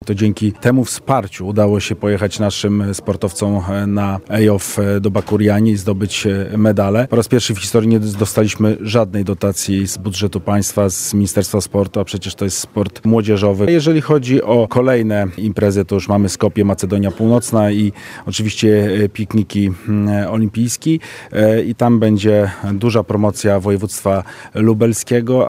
128 mln złotych przeznaczyło województwo lubelskie na sport w latach 2018-2025 – mówił na konferencji prasowej marszałek województwa lubelskiego Jarosław Stawiarski w odpowiedzi na słowa ministra sportu sprzed kilku dni.